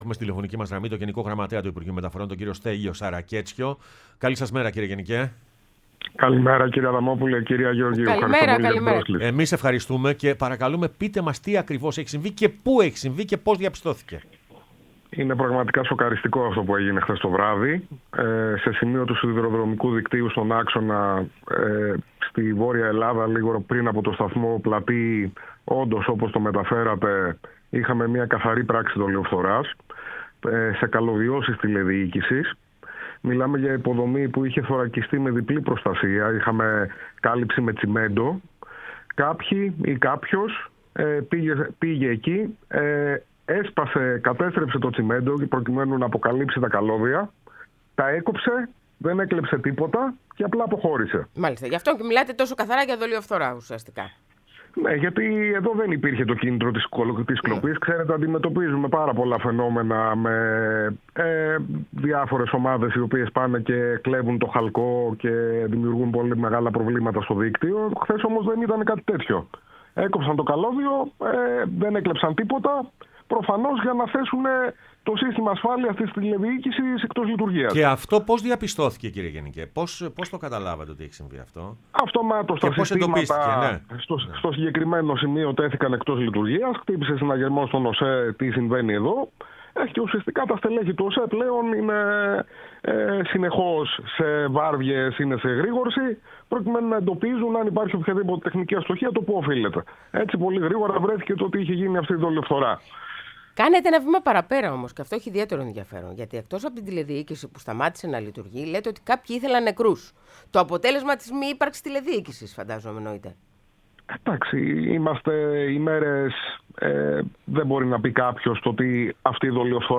Στέλιος Σακαρέτσιος, Γενικός Γραμματέας Υπουργείου Μεταφορών, μίλησε στην εκπομπή «Πρωινές Διαδρομές»